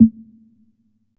TM-88 Snare #09.wav